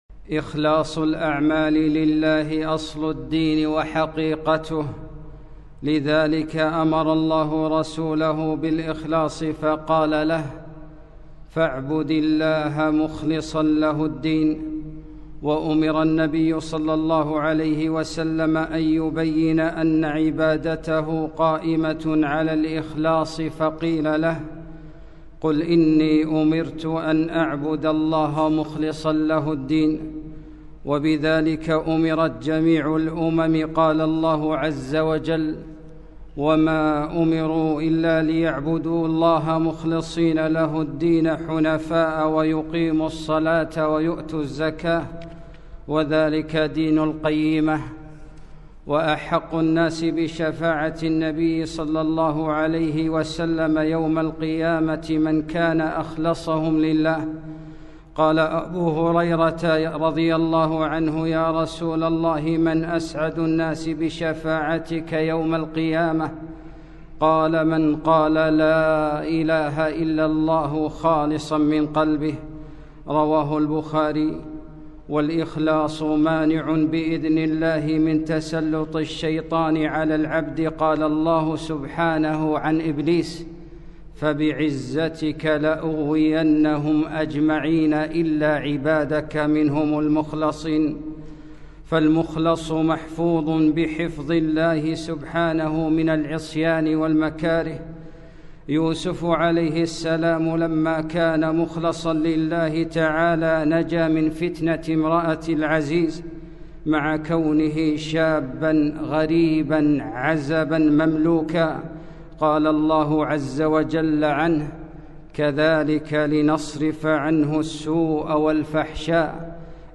خطبة - إنَّه من عبادنا المخلصين